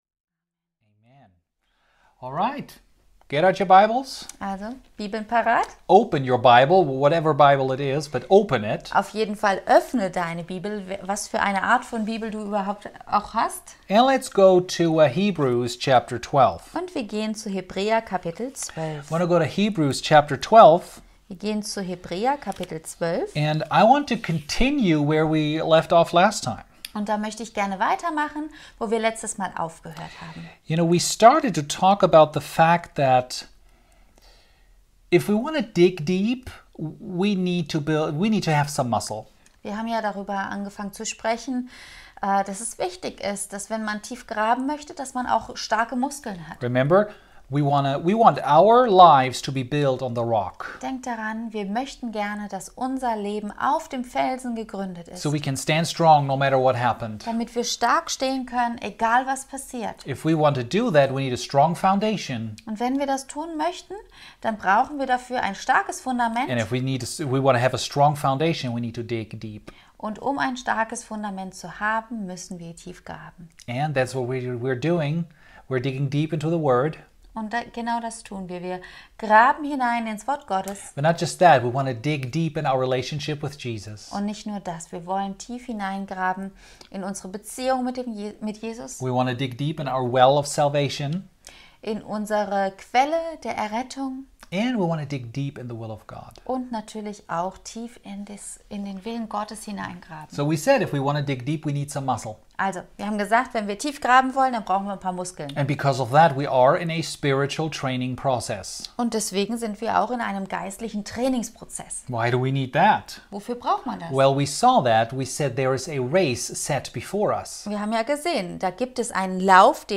Predigten // Sermon Archive by Rhema Bibel Gemeinde Bonn, Germany.